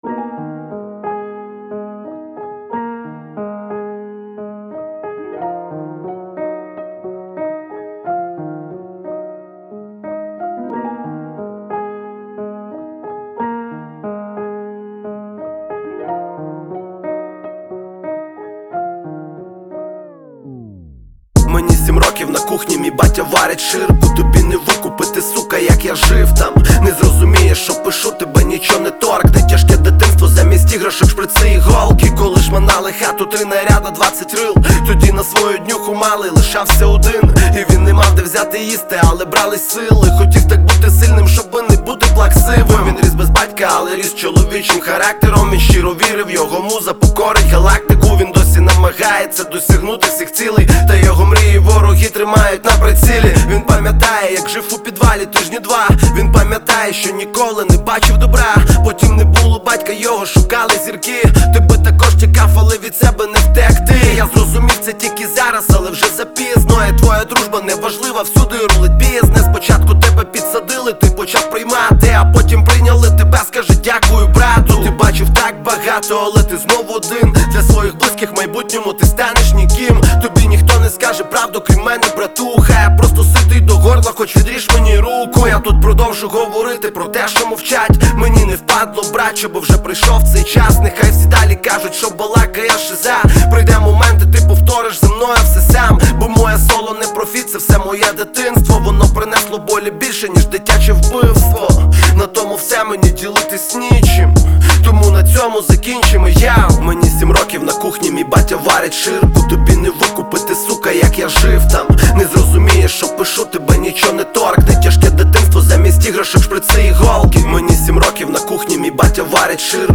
• Жанр: Rap, Hip-Hop